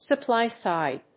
アクセントsupplý‐sìde